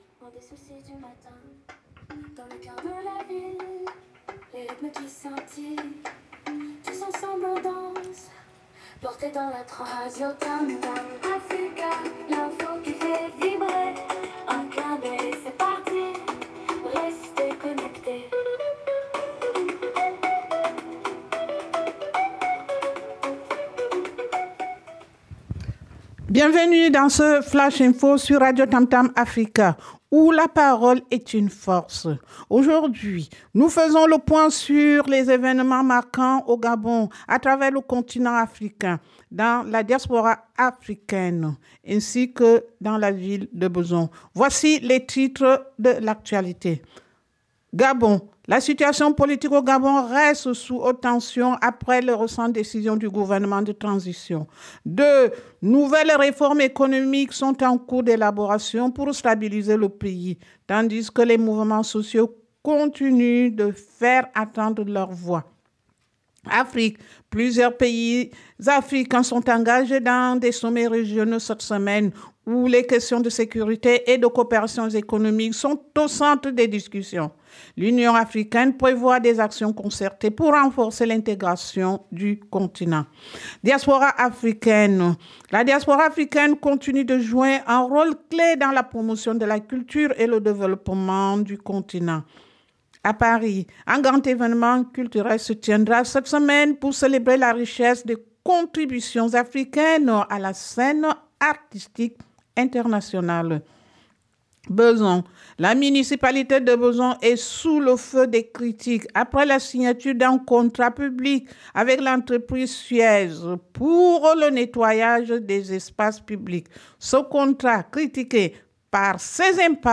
Radio TAMTAM AFRICA Flash Info : Gabon, Afrique, Diaspora Africaine, Bezons Flash Info – RADIOTAMTAM AFRICA : « La parole est 02 octobre 2024